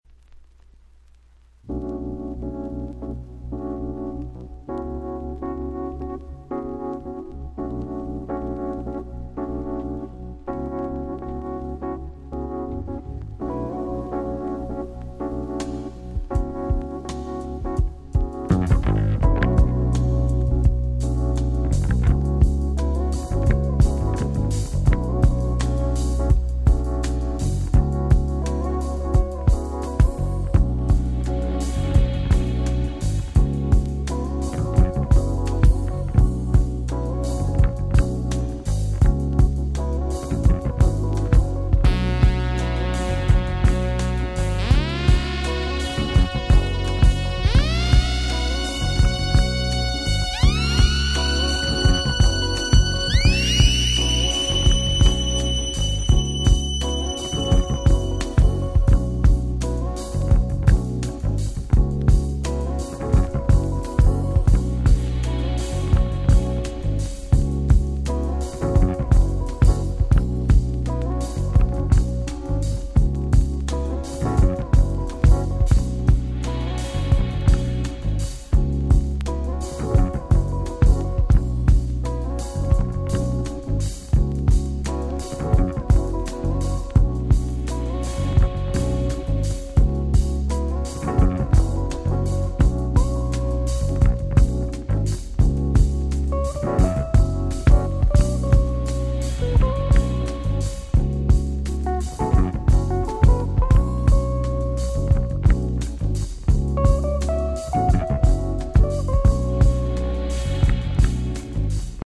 【Media】Vinyl 12'' Single (White)